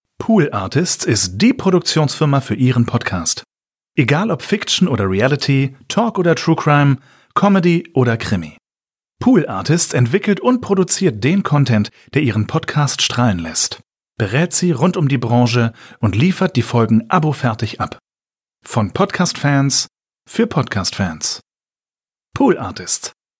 Hier sind unsere Stimmen zu hören, die ihr für eure Audio- und Podcast-Ads buchen könnt.